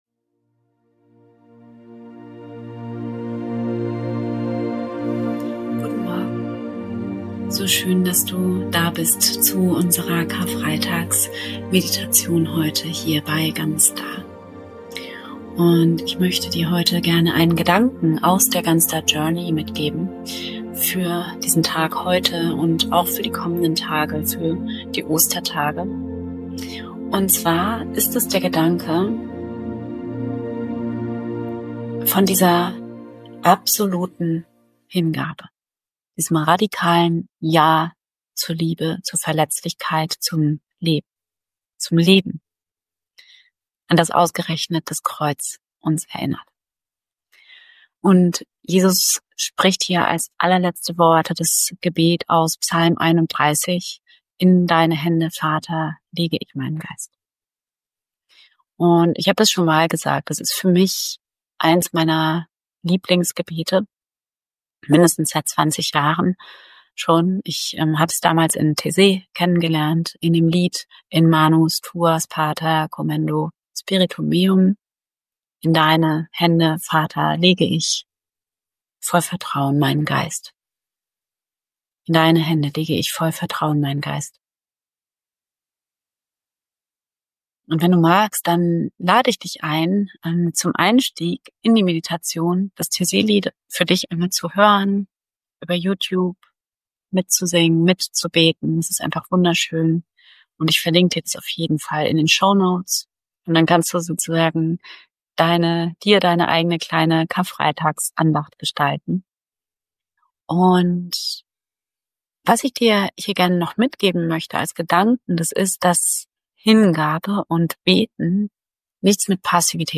In dieser besonderen Folge zum Karfreitag erwartet dich eine kraftvolle Meditation, die dich an das erinnert, worum es wirklich geht: Nicht um Schuld oder S...